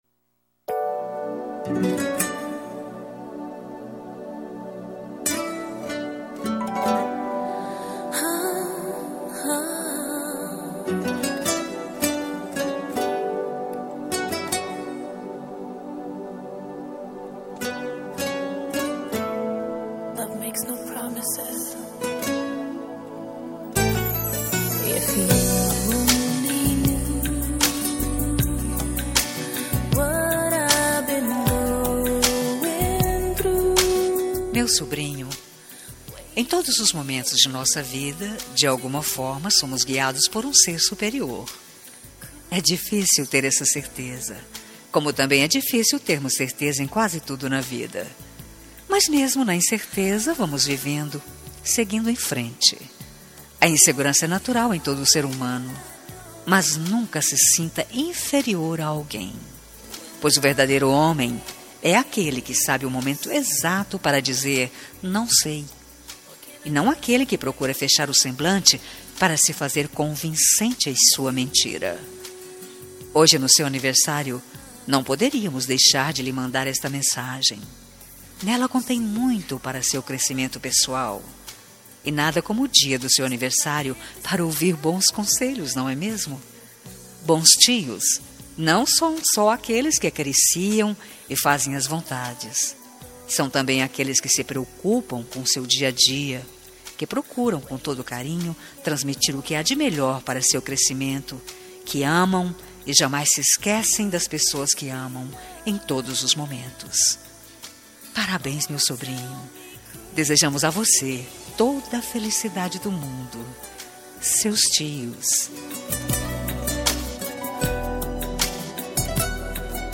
Aniversário de Sobrinho – Voz Feminina – Cód: 2661 – Plural
2661-sobrinho-fem-plural.m4a